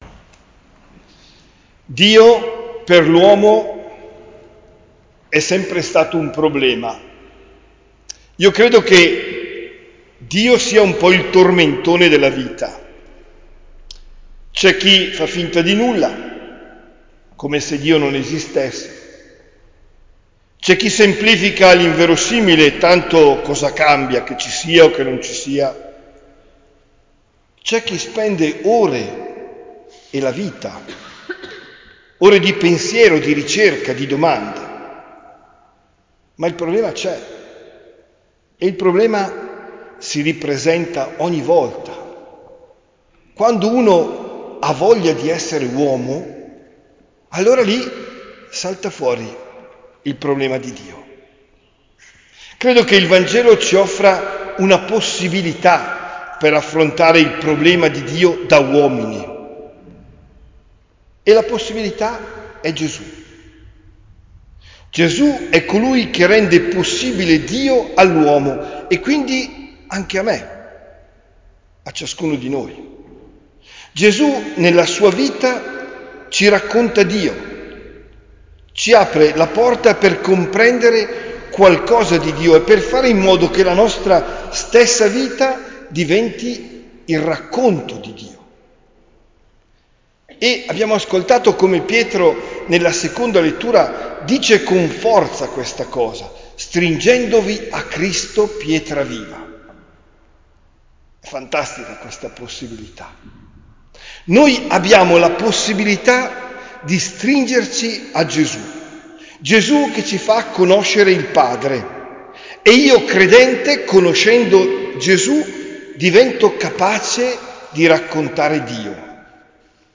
OMELIA DEL 7 MAGGIO 2023